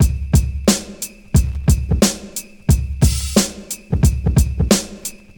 • 89 Bpm 1990s Hip-Hop Drum Loop C# Key.wav
Free drum groove - kick tuned to the C# note. Loudest frequency: 1923Hz